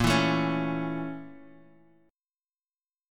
A/Bb chord
A-Major-Bb-6,7,7,6,x,x.m4a